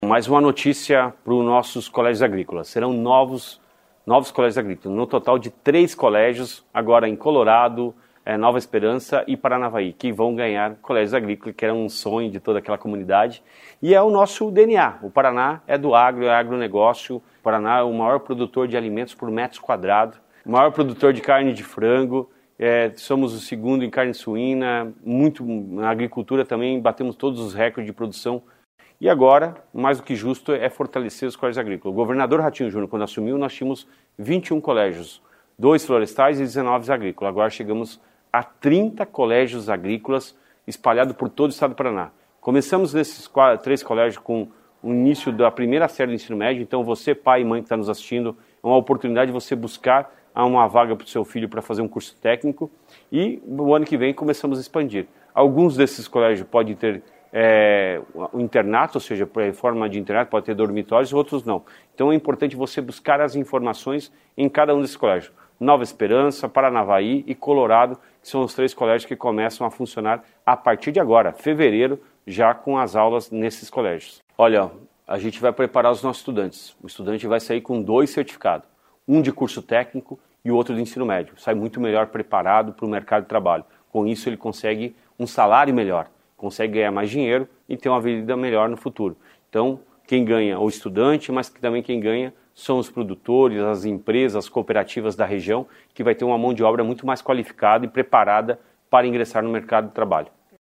Ouça o que diz o secretário de Educação do Paraná, Roni Miranda.